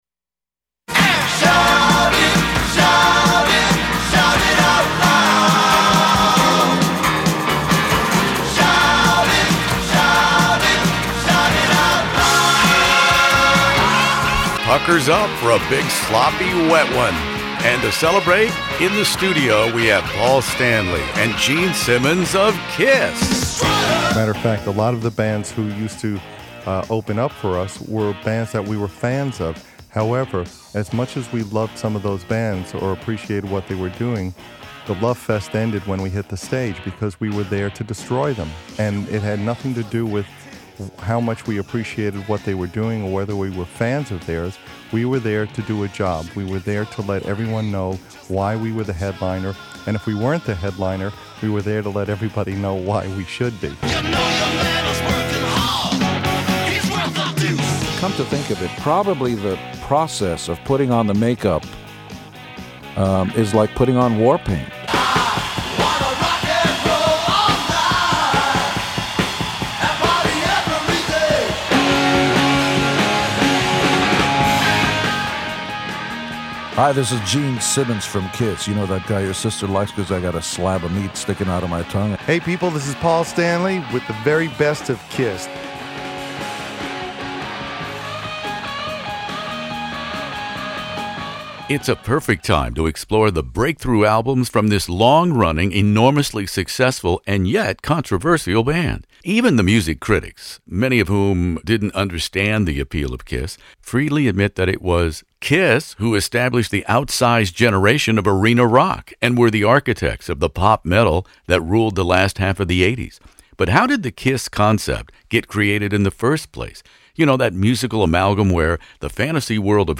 One of the world's largest classic rock interview archives, from ACDC to ZZ Top
Paul Stanley and Gene Simmons co-host In the Studio.